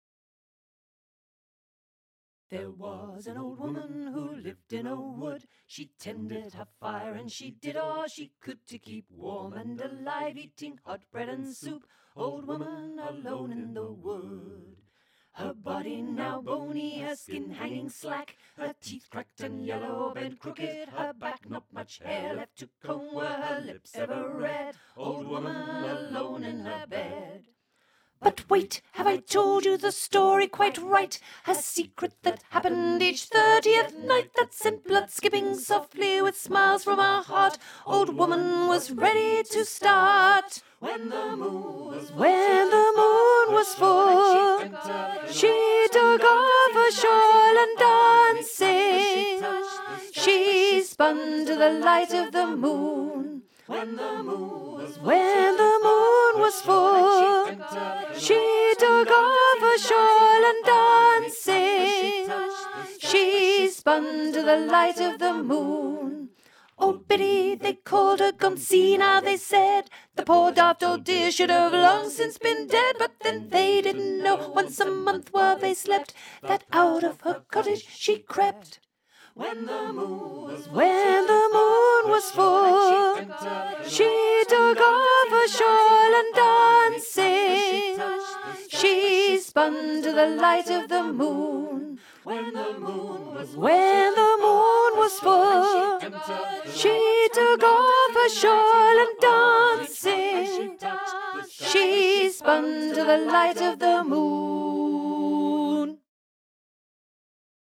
Vox Populi Choir is a community choir based in Carlton and open to all comers.